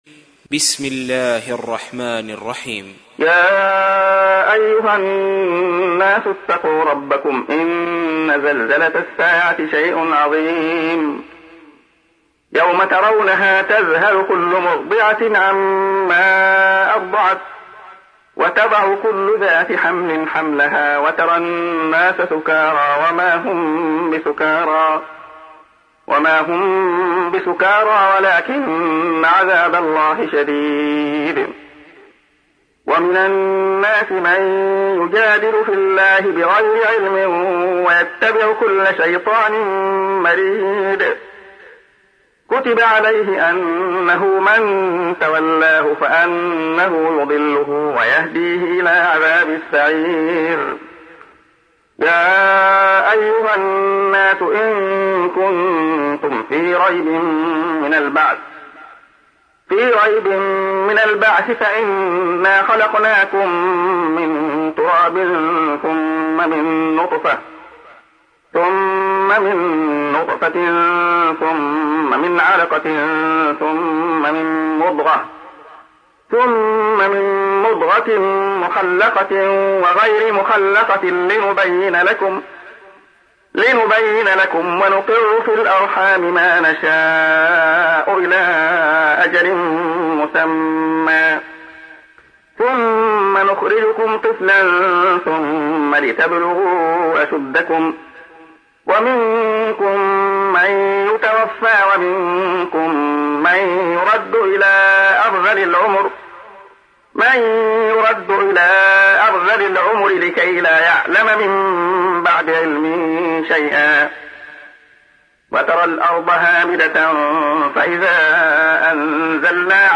تحميل : 22. سورة الحج / القارئ عبد الله خياط / القرآن الكريم / موقع يا حسين